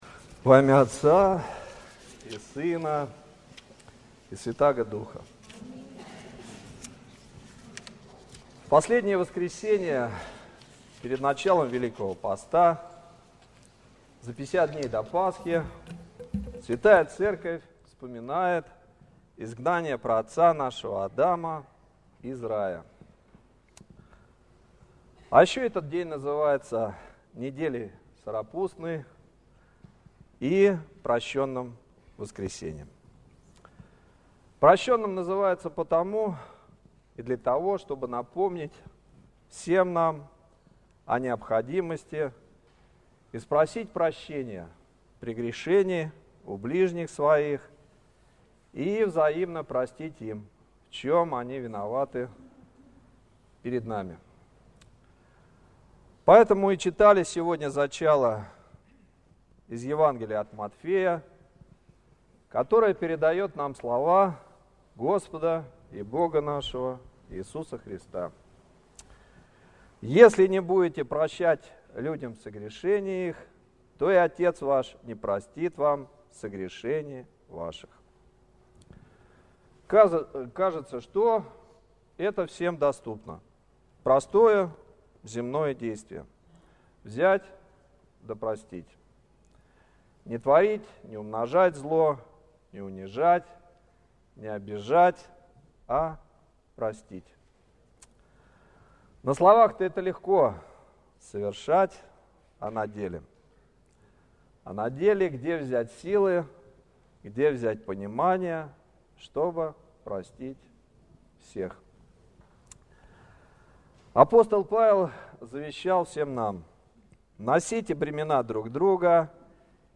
Слово в Прощеное воскресенье. О прощении